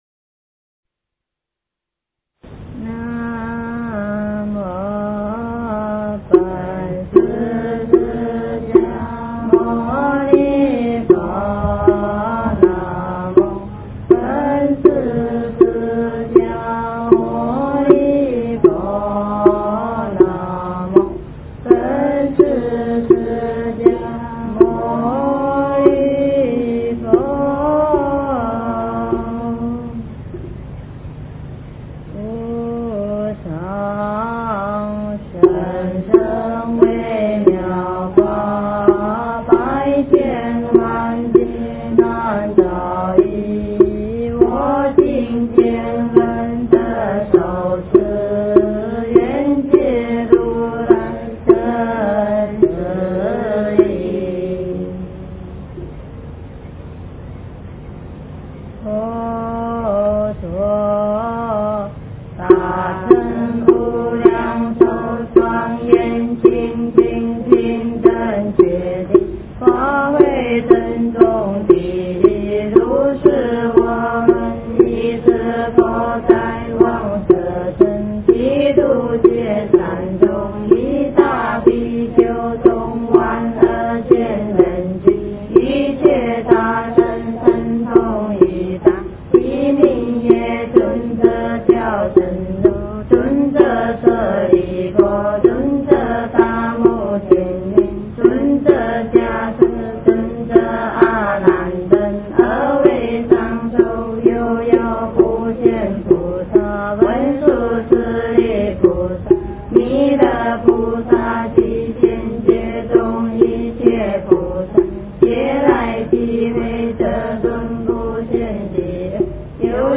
无量寿经--女众寺院版
无量寿经--女众寺院版 经忏 无量寿经--女众寺院版 点我： 标签: 佛音 经忏 佛教音乐 返回列表 上一篇： 阿弥陀佛赞偈--中峰禅寺 下一篇： 佛宝赞--寺院唱颂版1 相关文章 三稽首--如是我闻 三稽首--如是我闻...